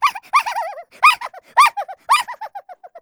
whackaMole_longLaugh.wav